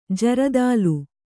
♪ jaradālu